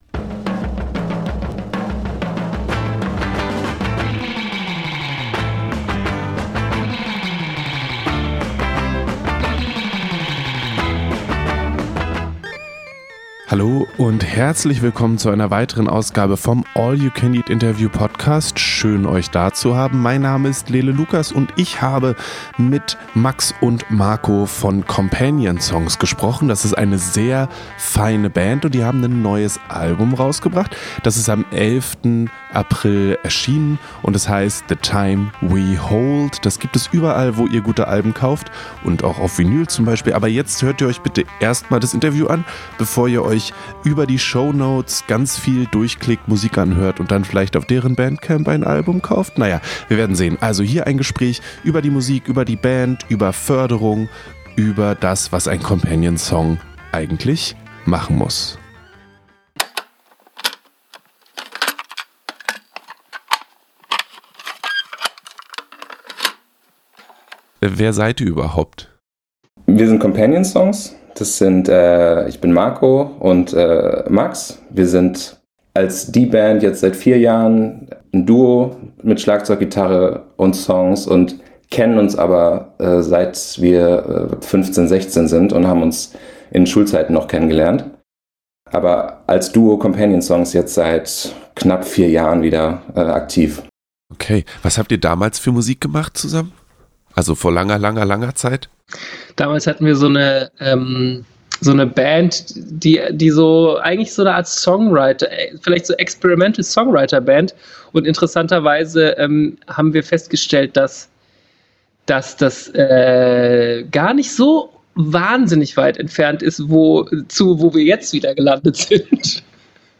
interview-mit-companion-songs.mp3